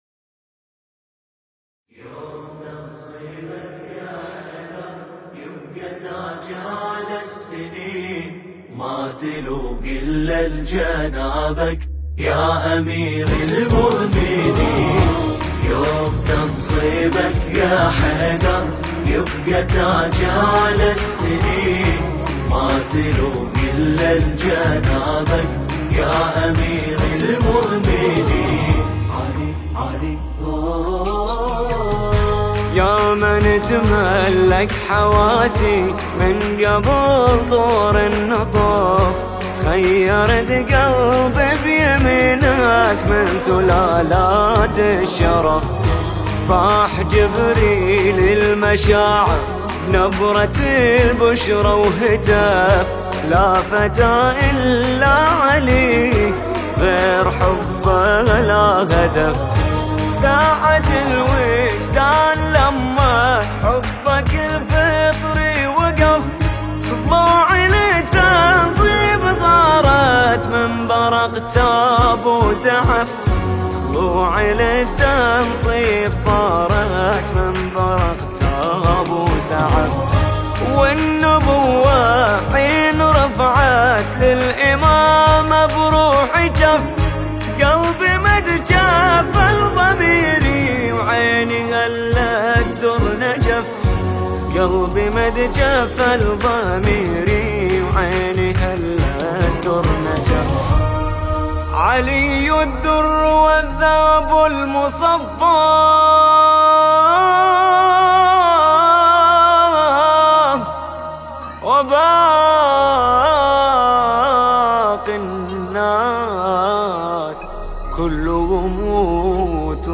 •أداء
•التوزيع
•الهندسة الصوتية والماستر